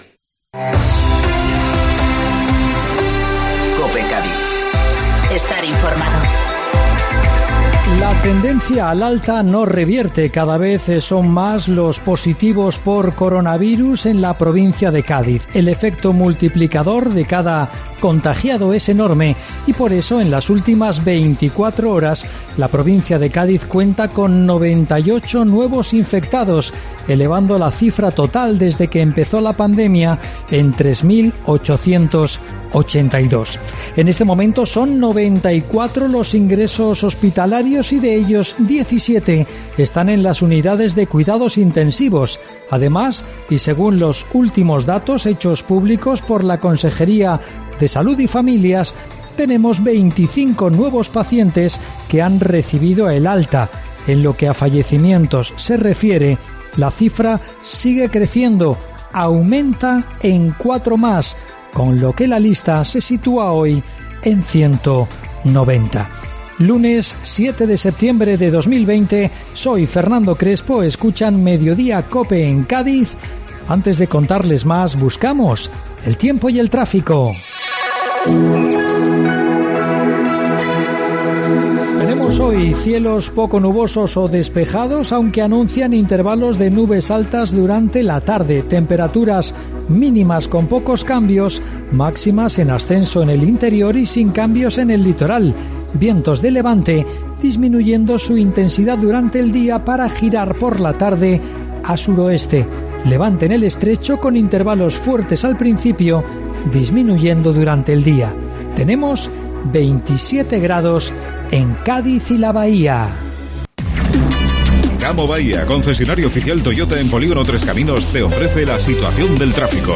Informativo Mediodía COPE Cádiz (7-9-2020)